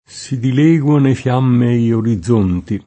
fiammeo [fL#mmeo] agg. — voce poet. per «fiammeggiante»: Si dilegua ne’ fiammei orizzonti [
Si dil%gUa ne fL#mme-i orizz1nti] (D’Annunzio) — più raro flammeo, che ha però anche alcuni sign. particolari (med., stor.)